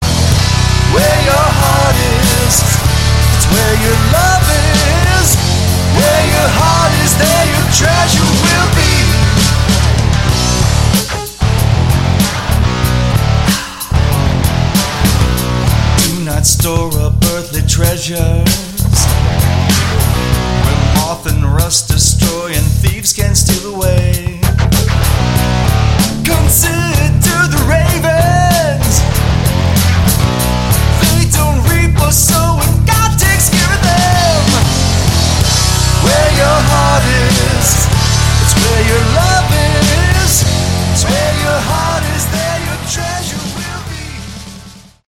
Category: Hard Rock
Guitar, Vocals
Bass, Vocals